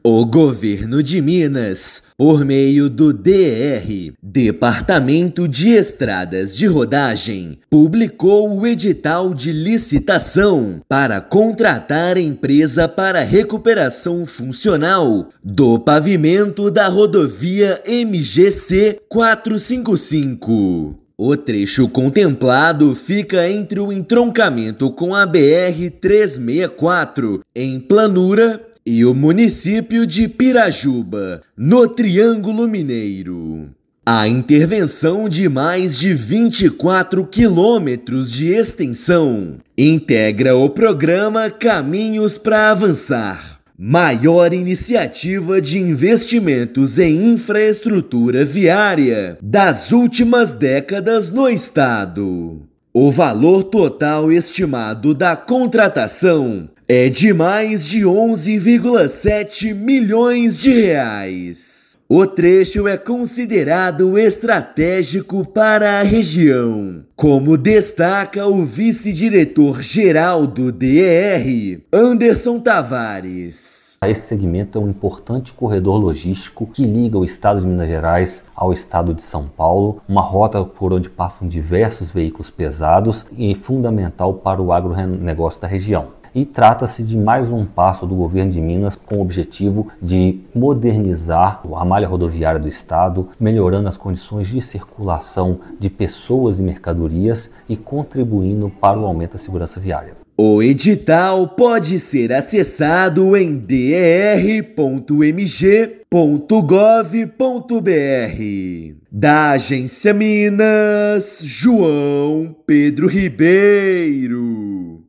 Intervenção reforça corredor logístico essencial para o transporte de cargas. Ouça matéria de rádio.